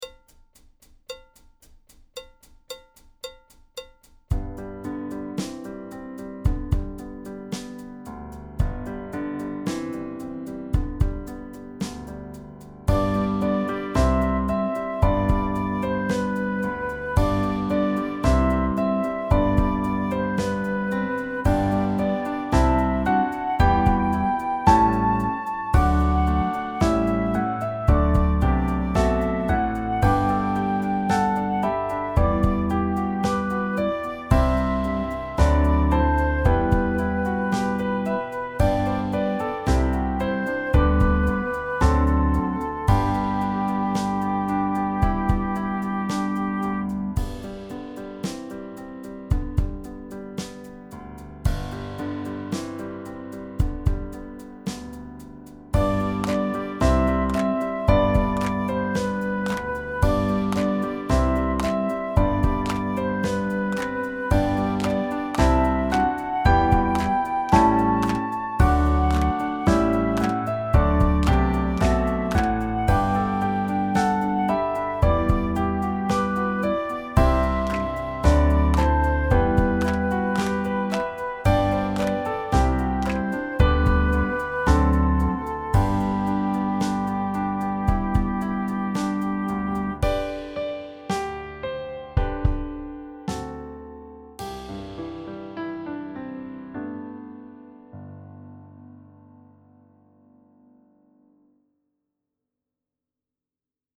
• G Major setting with simple notation and patterns